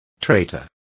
Complete with pronunciation of the translation of traitors.